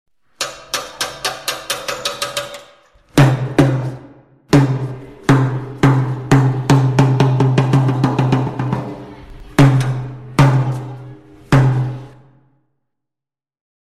Tiếng Trống tan trường ra về
Thể loại: Tiếng đồ vật
Description: Tiếng trống tan trường ra về là âm thanh tiếng trồng trường báo hiệu kết thúc thời gian học của một ngày, là hiệu ứng âm thanh trường học xen lẫn tiếng cười nói của học sinh khi được tan học, hết giờ ra vềlà tiếng trống trường giòn giã, thúc giục.
tieng-trong-tan-truong-ra-ve-www_tiengdong_com.mp3